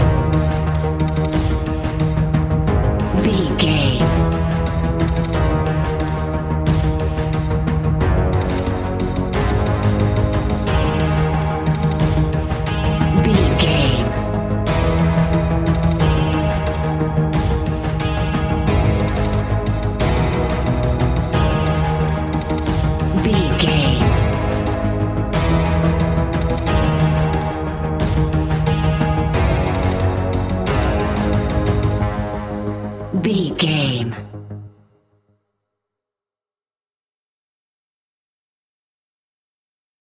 Aeolian/Minor
G#
ominous
dark
eerie
drums
percussion
synthesiser
ticking
electronic music